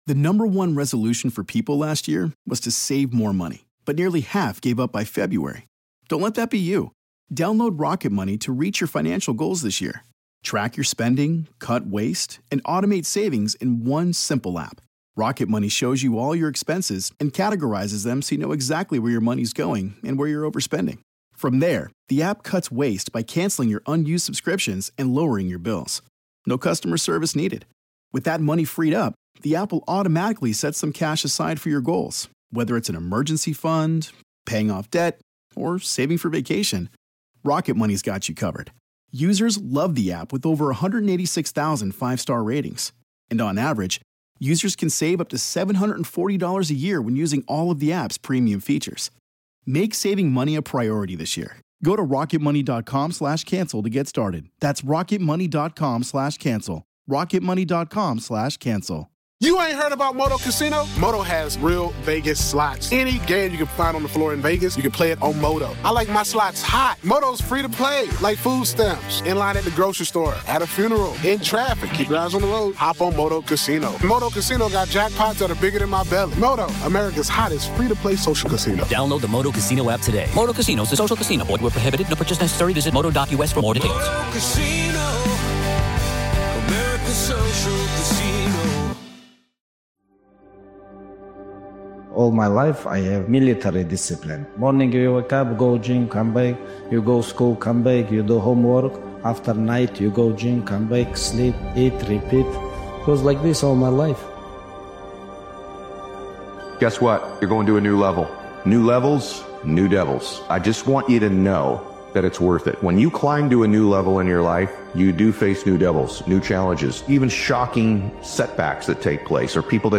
This powerful motivational speeches compilation captures the energy of someone who refuses to stay down—someone rebuilding in silence, sharpening their mindset, and preparing for a stronger return. Setbacks don’t define you; your comeback does.